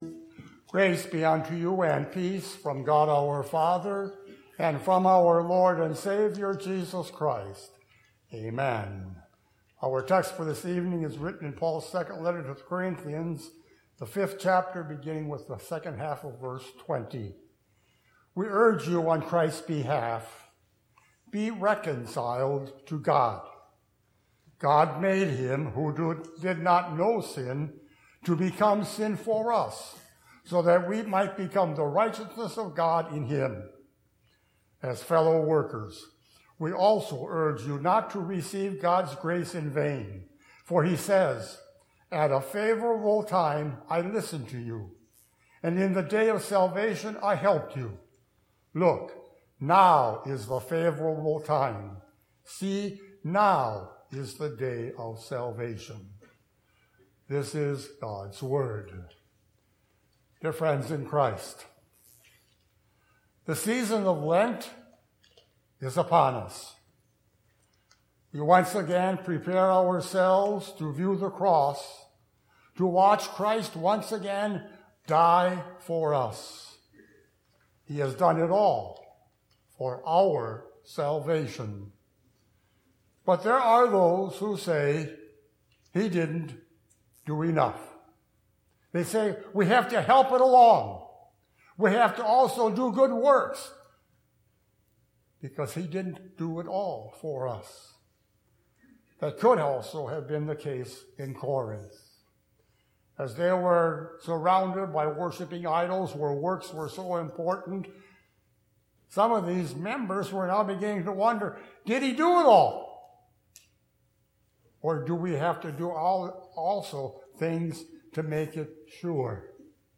Services (the most recent service is in the first box)